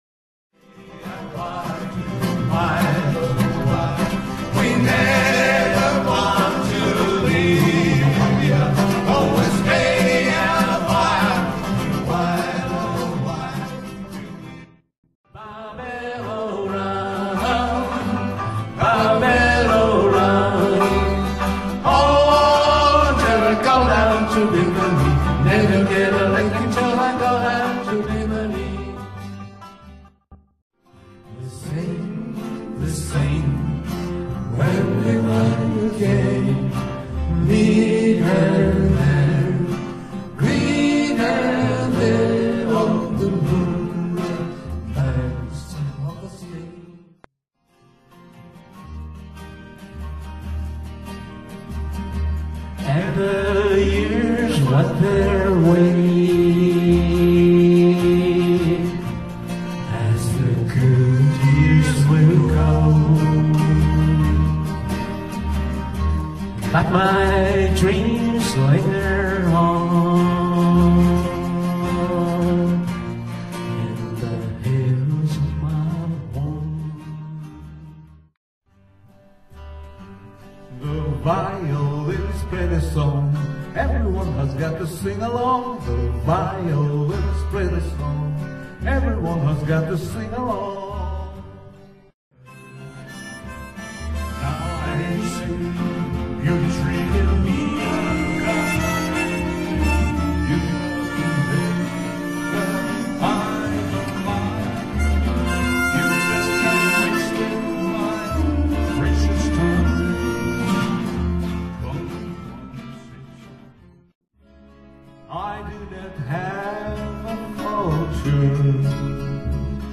今日の練習の模様はこちらから一部録音を聴くことができます。